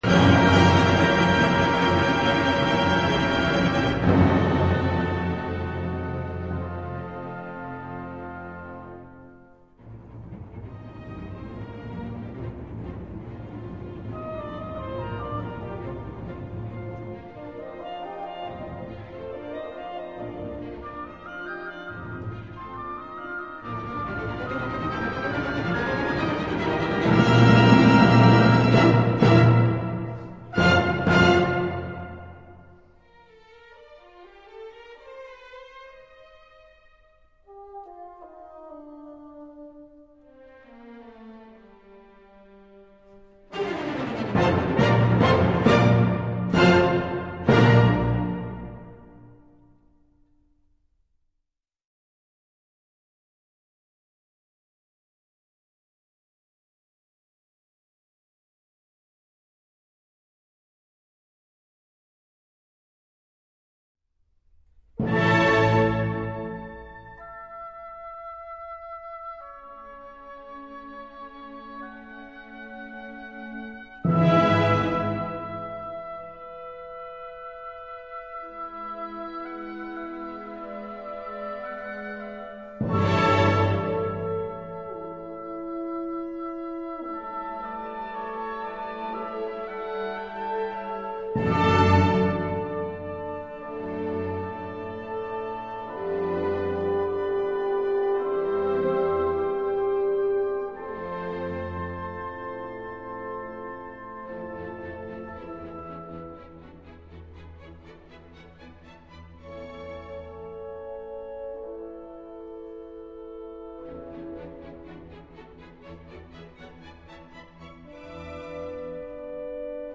【交響曲】
整首樂曲優美輕快，雖然編制不大，
卻極其穩健雅緻，充滿著明朗快活的情調，是一首親切自然的傑作。